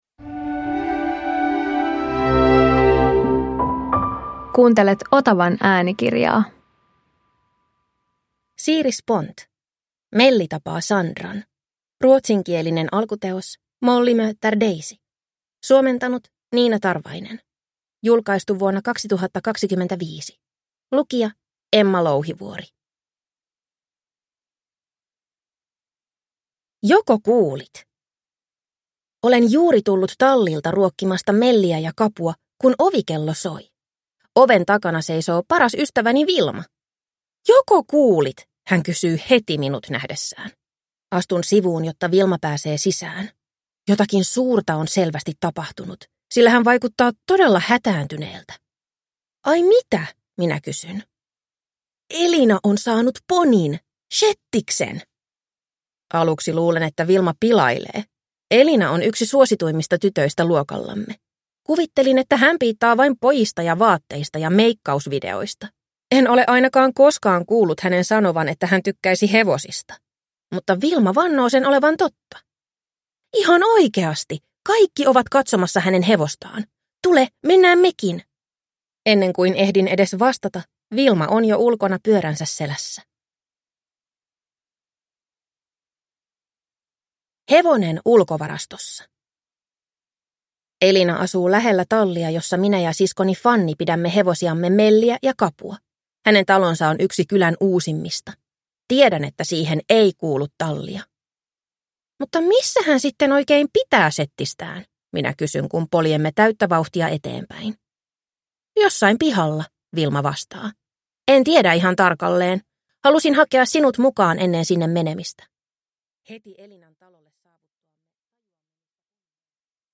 Melli tapaa Sandran – Ljudbok